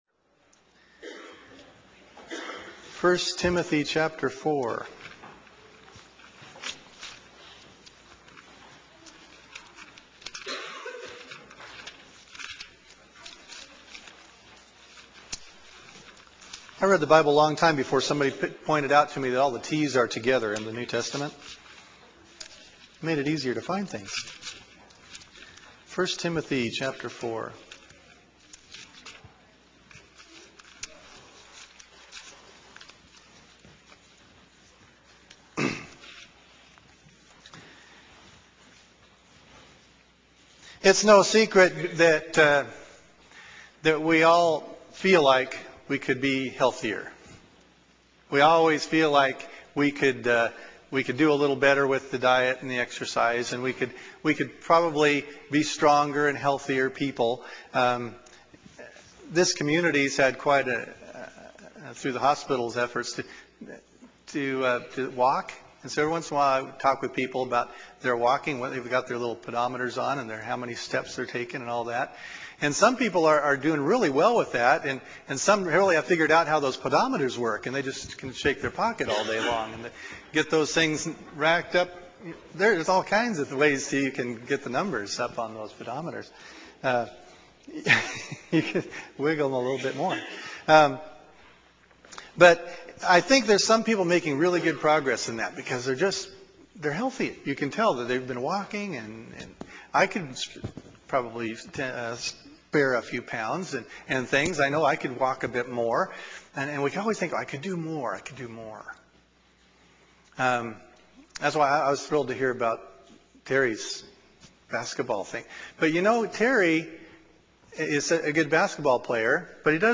To listen to sermons (Click on sermon title below) If you want to download the sermon, right click on sermon title and select "Save target as ..."